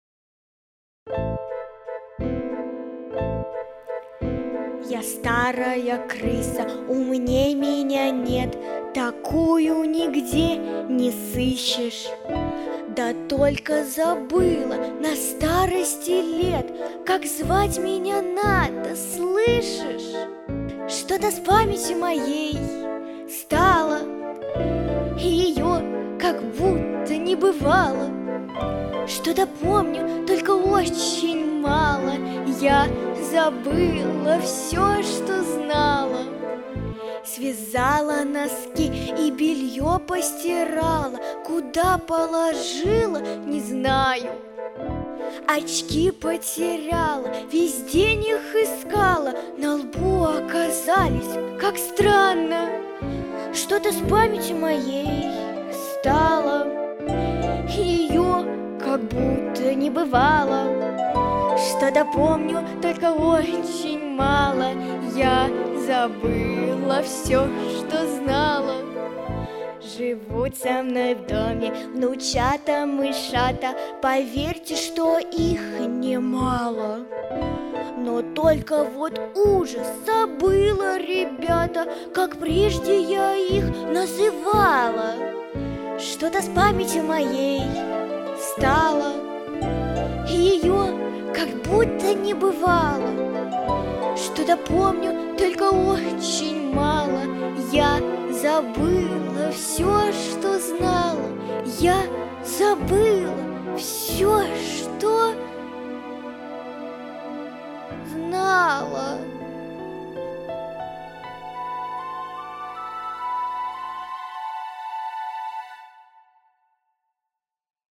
Записано в студии Easy Rider в декабре 2019 года